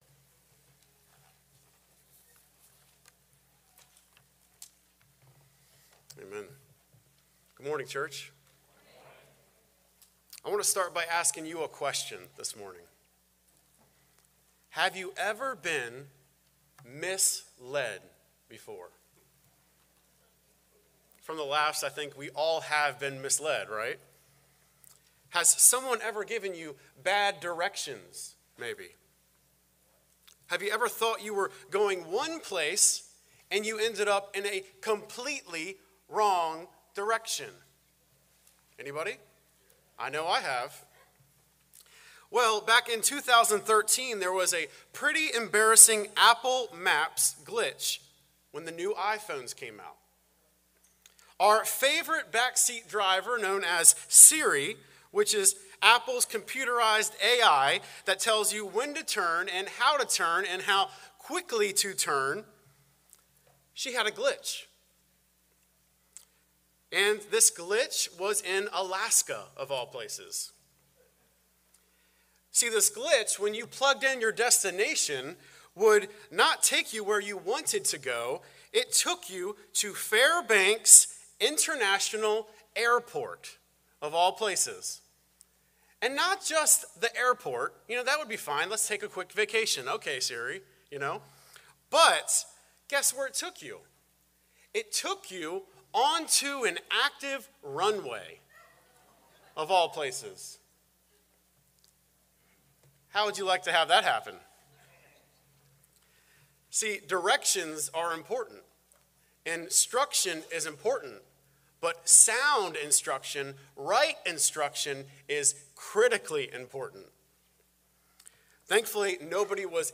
A message from the series "Behold our God!." Psalm 115 Introduction There are many who have suffered significant trauma in life.
FBCUM-Live-Stream-82122.mp3